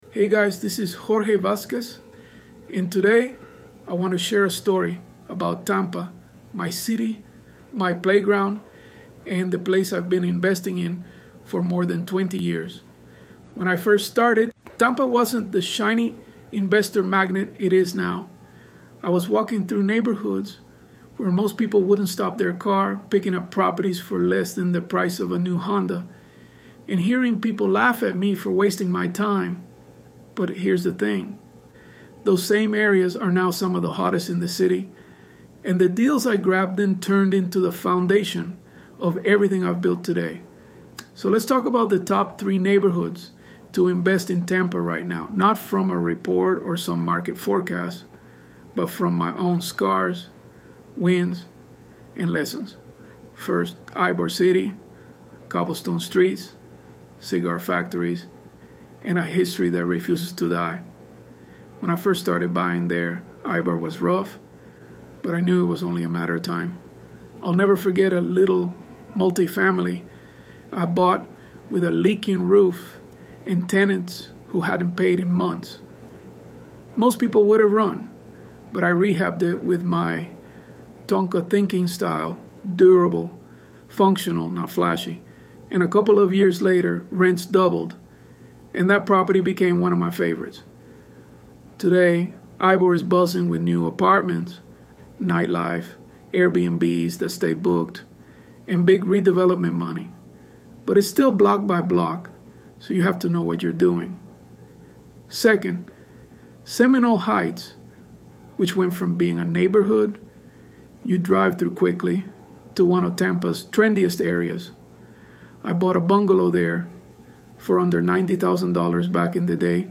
ElevenLabs_Untitled_Project-22.mp3